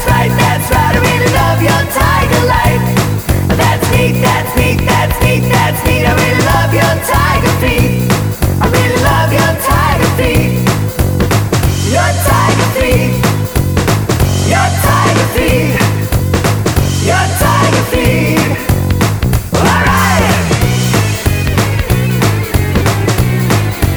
no Backing Vocals Glam Rock 3:51 Buy £1.50